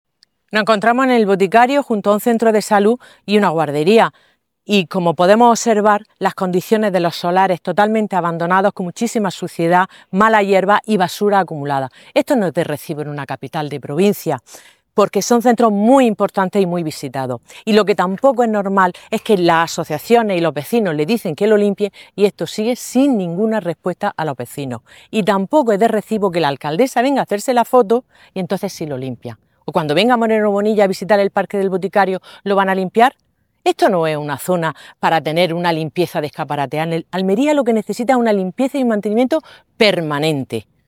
La concejala del PSOE en el Ayuntamiento de Almería, Carmen Aguilar, ha reclamado a la Junta de Andalucía la limpieza inmediata y el mantenimiento periódico de los solares que hay junto a ambos equipamientos ya que son de titularidad autonómica.